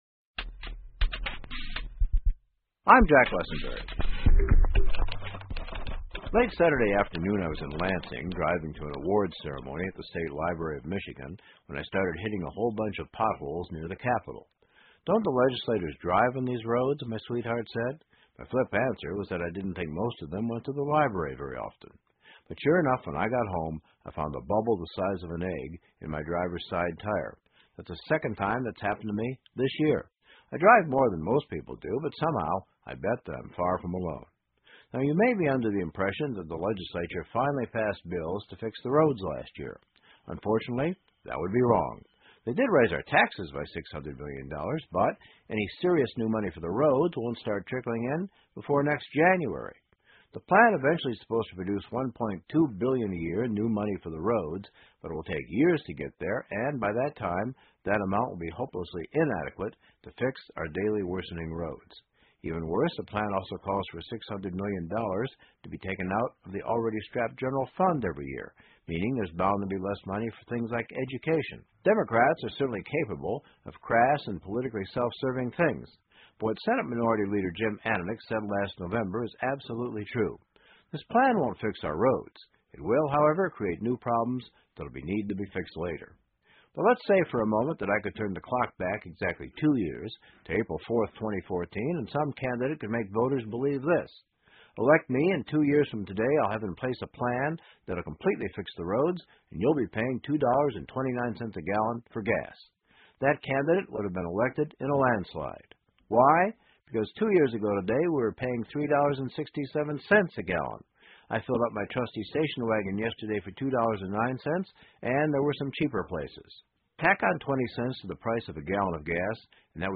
密歇根新闻广播 密歇根坑坑洼洼的马路问题何时解决 听力文件下载—在线英语听力室